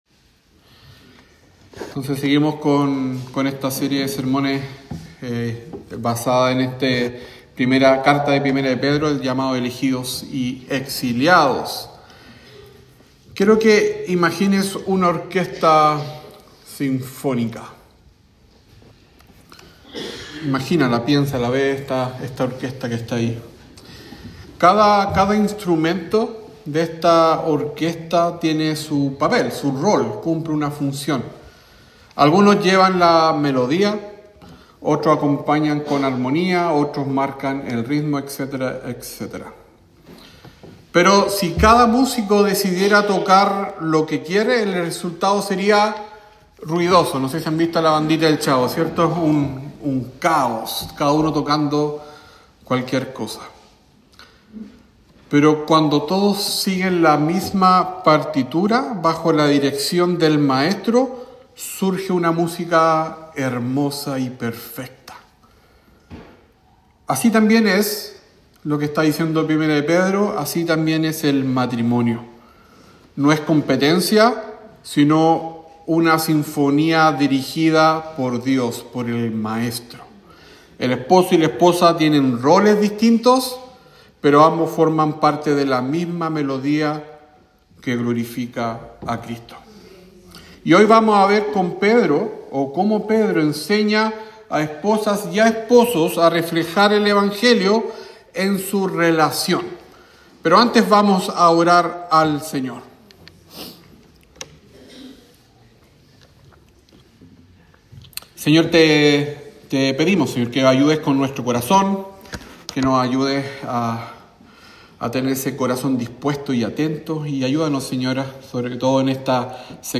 Sermón sobre 1 Pedro 3